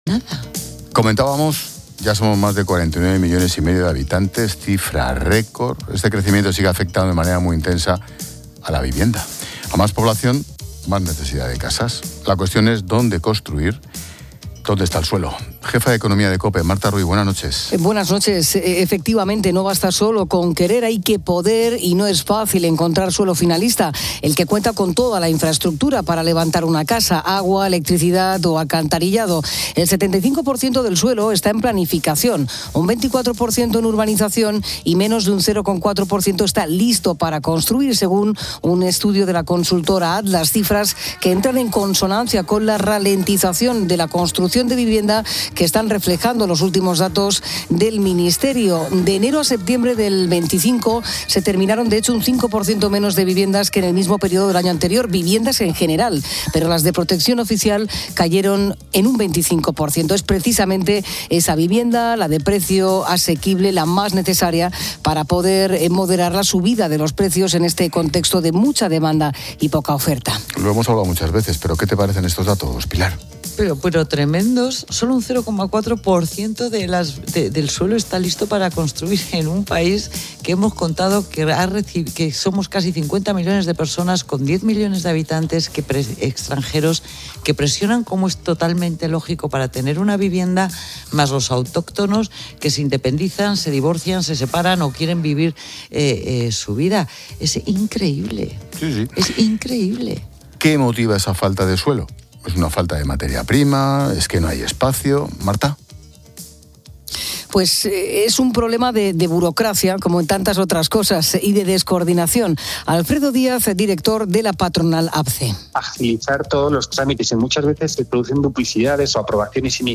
Expósito aprende en Clases de Economía de La Linterna con la experta económica y directora de Mediodía COPE, Pilar García de la Granja, sobre el problema de la vivienda en España: falta de suelo y burocracia